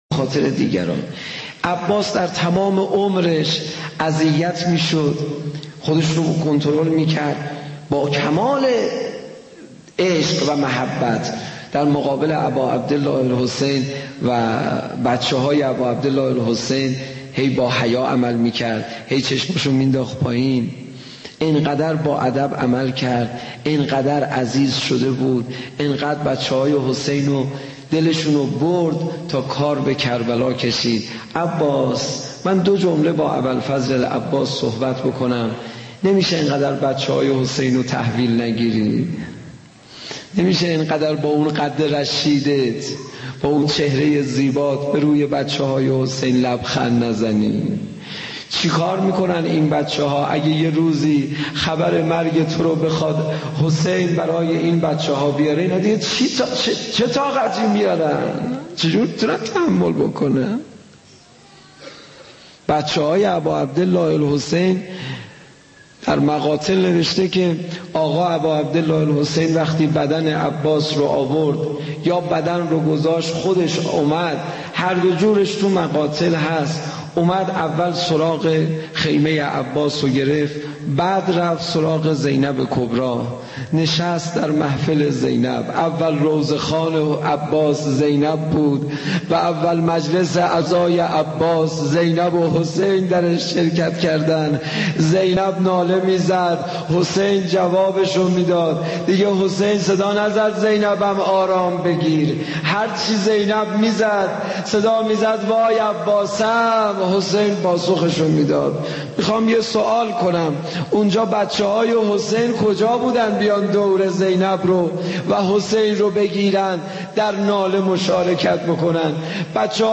دسته : پاپ